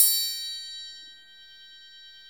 Index of /90_sSampleCDs/Roland LCDP03 Orchestral Perc/PRC_Orch Toys/PRC_Orch Triangl
PRC TRI1OPEN.wav